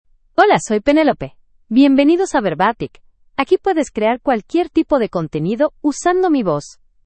Penelope — Female Spanish (United States) AI Voice | TTS, Voice Cloning & Video | Verbatik AI
Penelope is a female AI voice for Spanish (United States).
Voice sample
Listen to Penelope's female Spanish voice.
Penelope delivers clear pronunciation with authentic United States Spanish intonation, making your content sound professionally produced.